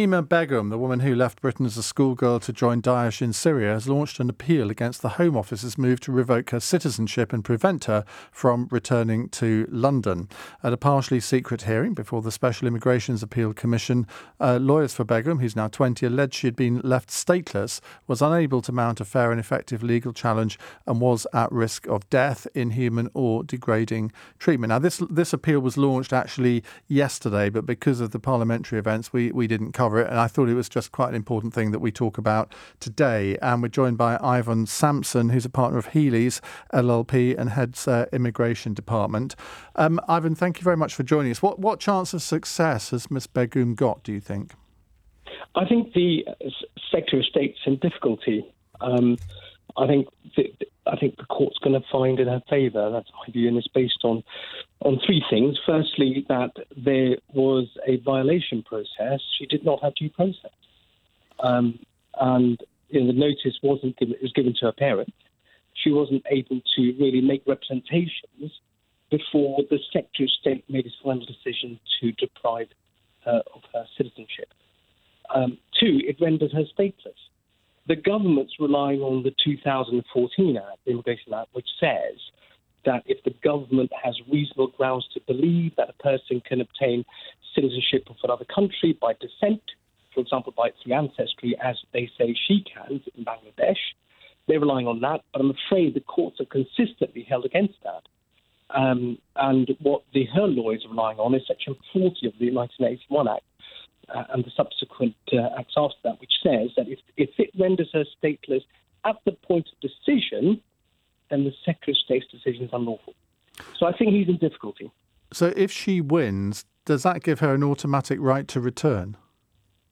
Listen to the full radio interview here: